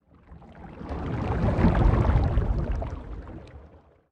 Sfx_creature_glowwhale_swim_slow_01.ogg